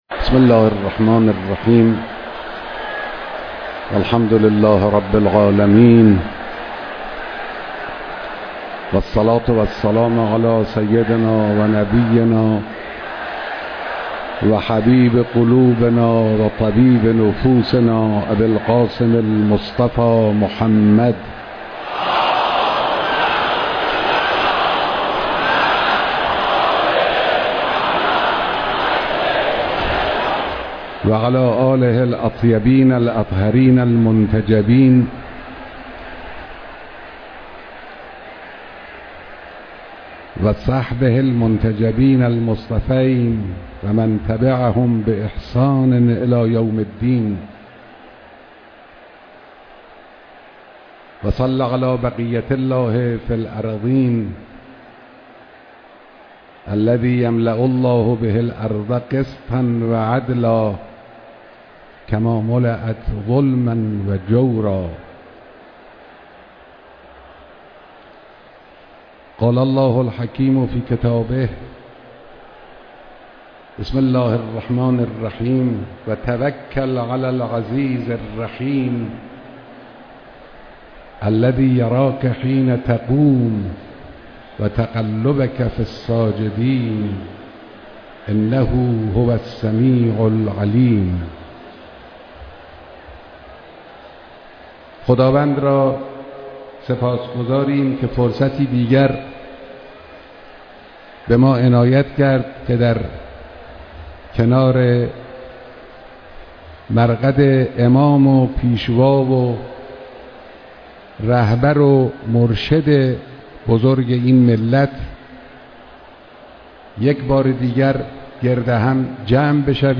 بیانات در مراسم گرامیداشت بیست و سومین سالگرد رحلت امام خمینی (ره)